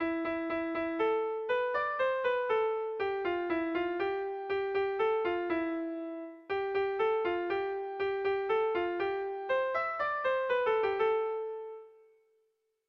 Bertso melodies - View details   To know more about this section
ABD